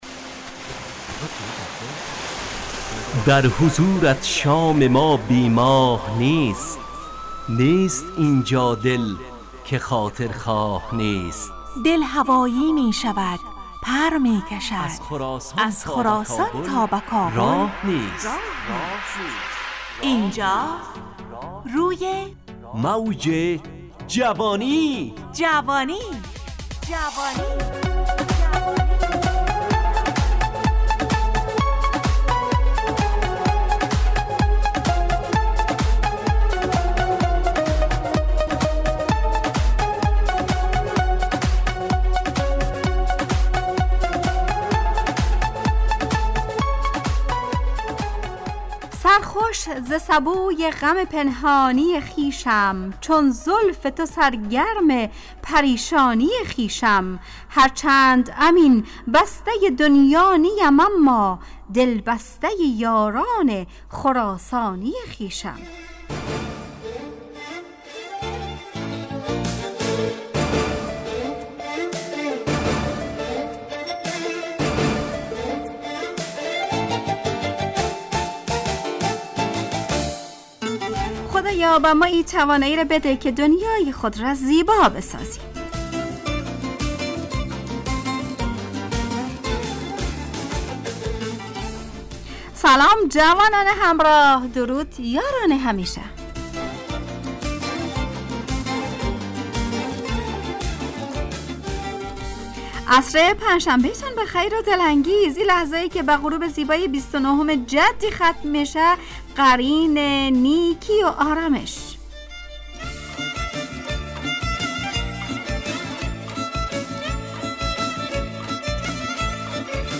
روی موج جوانی، برنامه شادو عصرانه رادیودری.
همراه با ترانه و موسیقی مدت برنامه 55 دقیقه . بحث محوری این هفته (دنیا) تهیه کننده